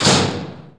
METALDOOR1.WAV